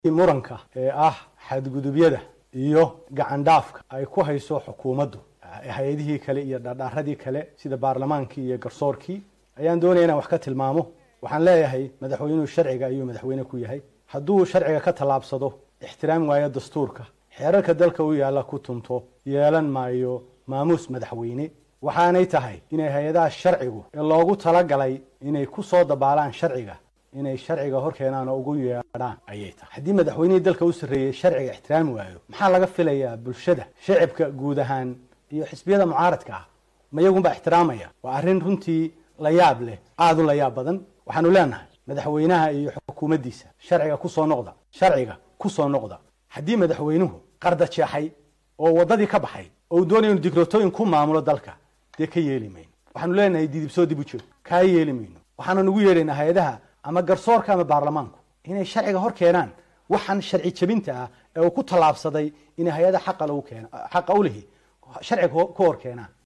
Gudoomiyaha xisbiga mucaaradka Soomaaliland ee Wadani Xirsi Cali Xaaji Xassan oo maanta warbaahinta kula hadlay magaalada hargaysa ayaa eedaymo u jeediyay